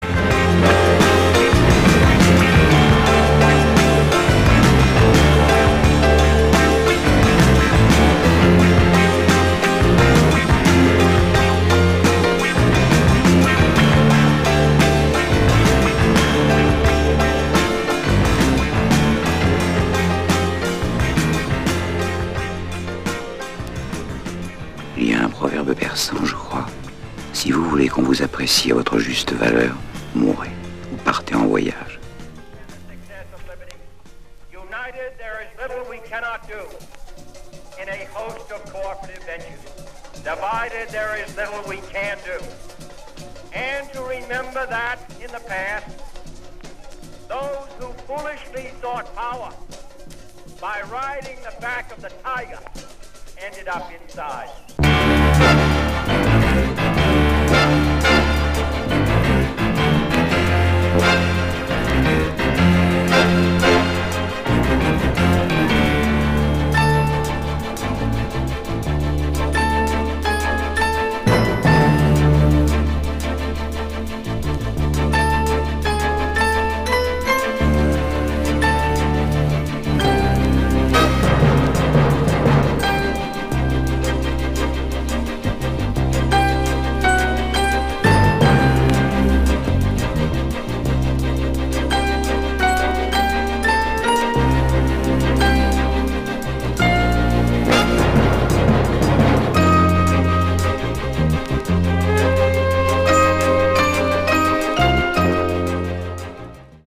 Superb groove
Powerful breaks !